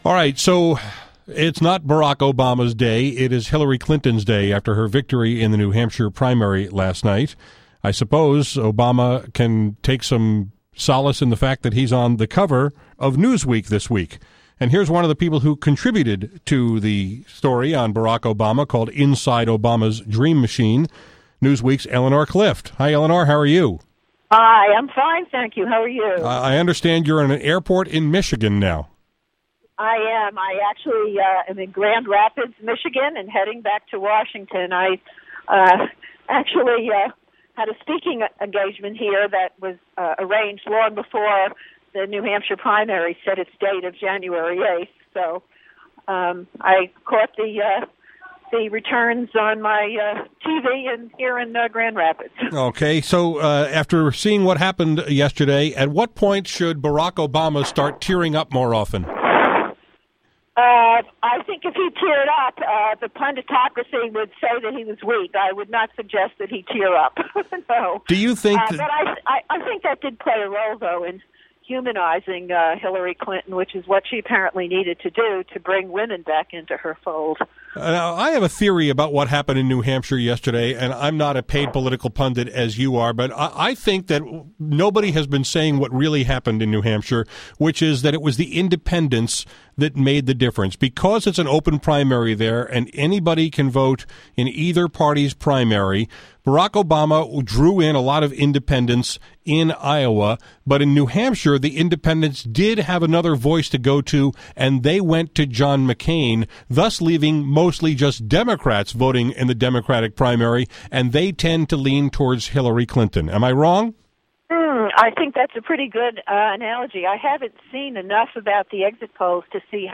Newsweek’s Eleanor Clift was on my show this afternoon to talk about the presidential race, and their cover story on Barack Obama (which she contributed to).